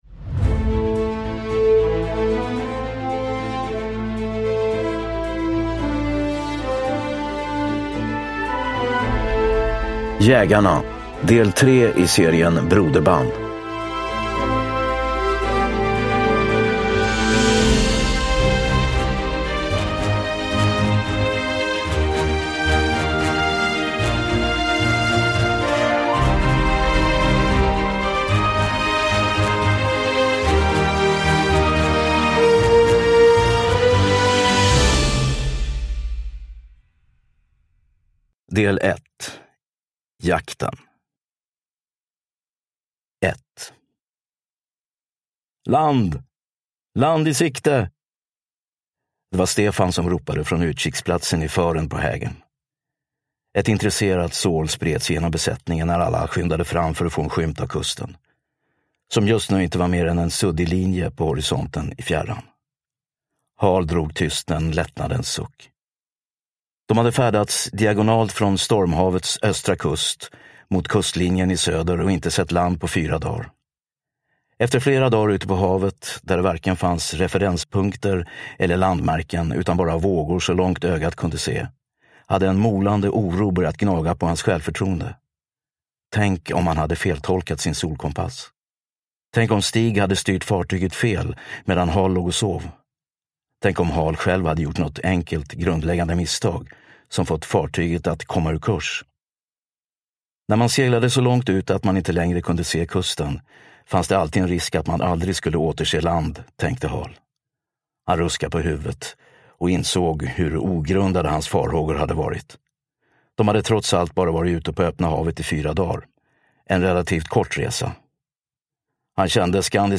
Jägarna – Ljudbok – Laddas ner